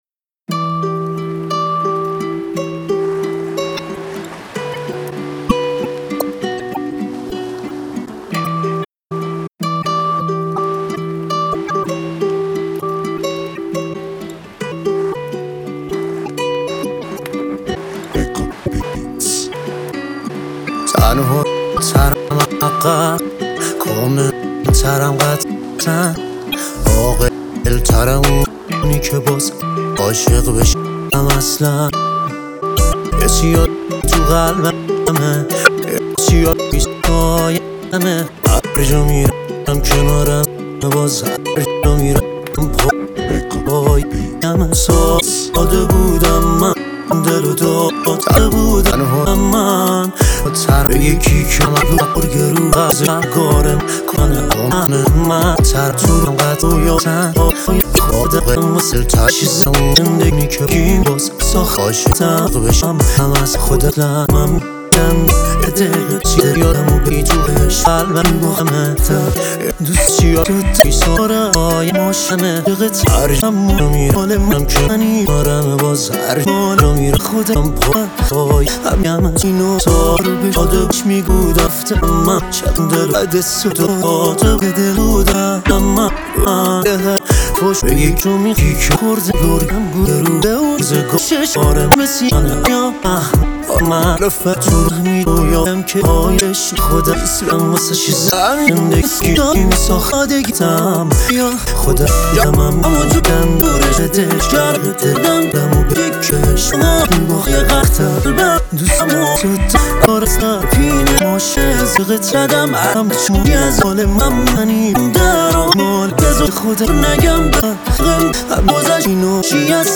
دانلود اهنگ پاپ